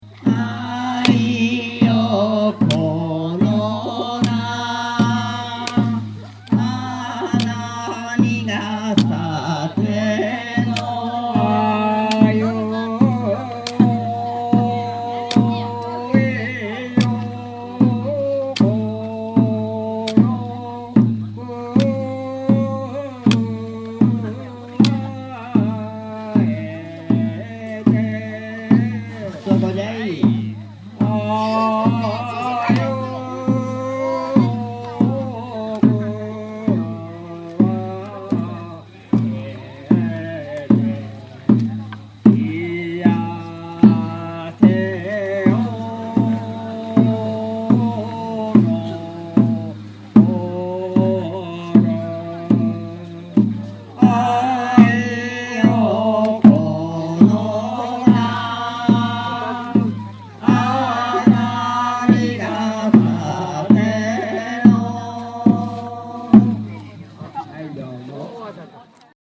伏拝の盆踊り
伏拝（ふしおがみ）は熊野古道が通りぬける山里。盆踊りの起源は江戸の中期と伝わり、ゆるやかなテンポでしっとり踊られるものが多いです。この曲は「五尺いよこ」。